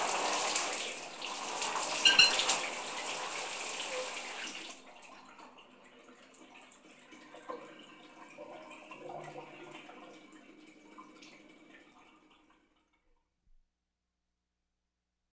showerend.wav